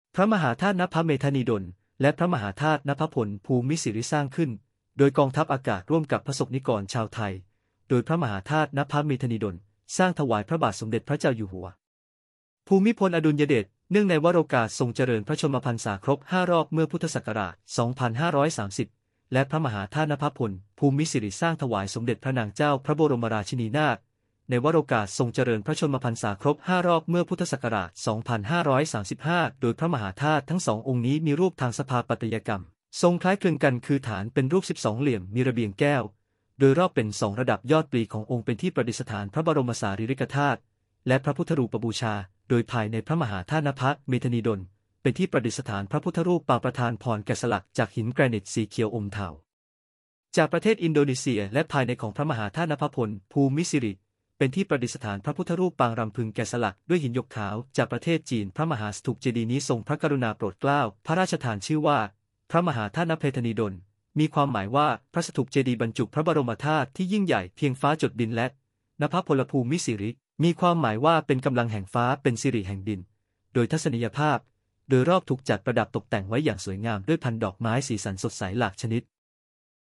เสียงบรรยากาศ